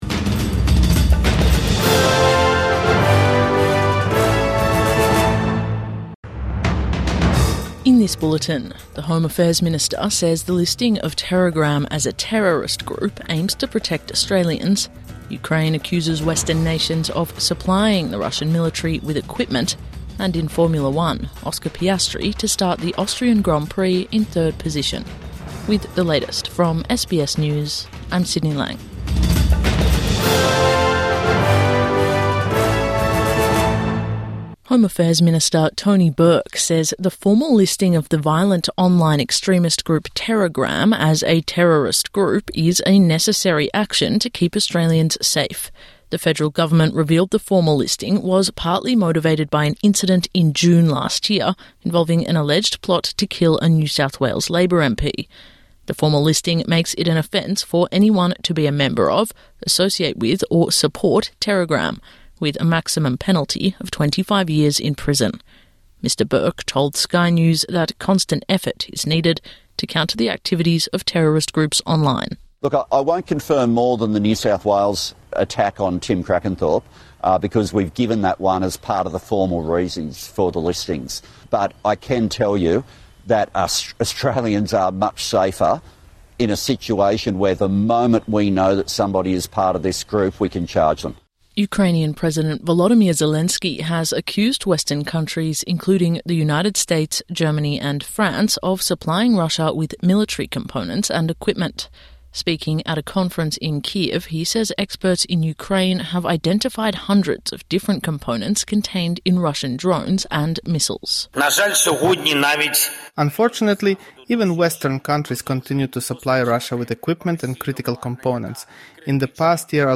Midday News Bulletin 29 June 2025